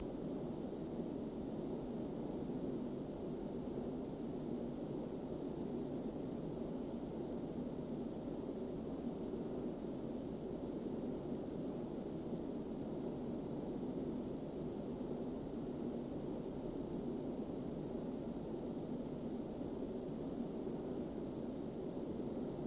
base-wind-space-platform.ogg